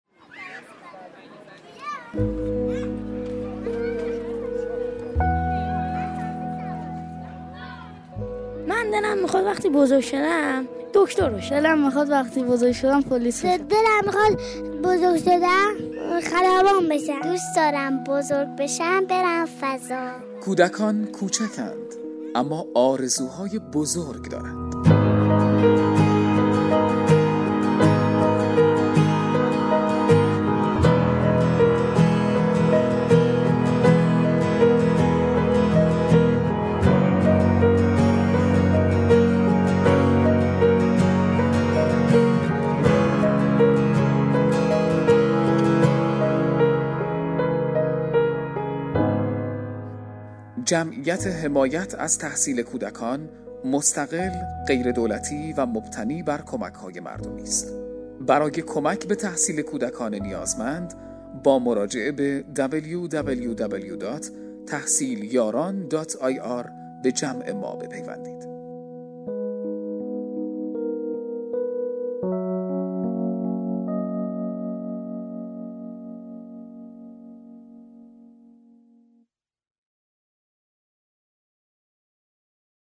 آگهی و فراخوان اجتماعی با موضوع کمک به تحصیل کودکان محروم.